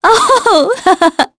Veronica-Vox_Happy3.wav